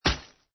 bodyhit.mp3